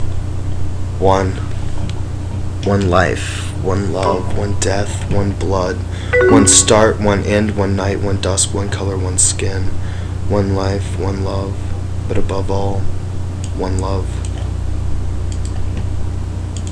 Main Index Table of Contents Hear me read One.